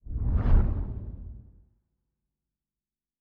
Distant Ship Pass By 4_1.wav